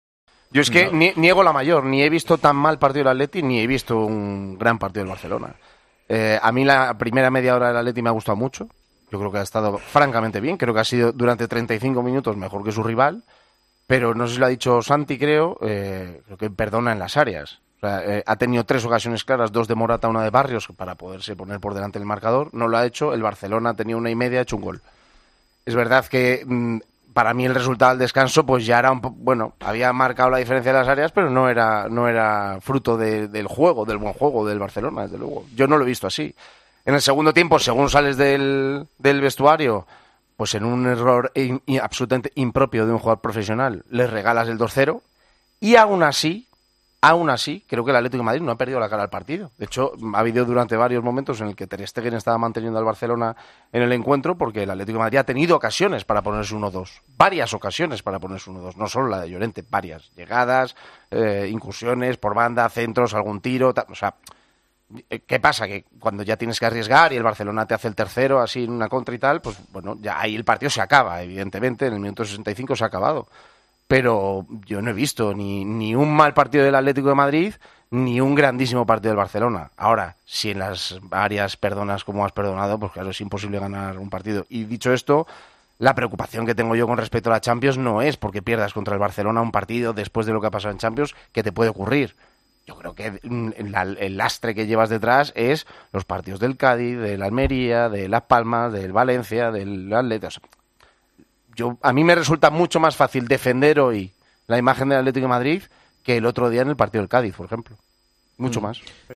El comentarista del Atlético de Madrid en Tiempo de Juego analizó la derrota del Atlético ante el Barcelona y señaló los puntos positivos del equipo de Simeone.